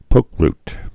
(pōkrt, -rt)